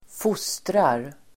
Uttal: [²f'os:trar]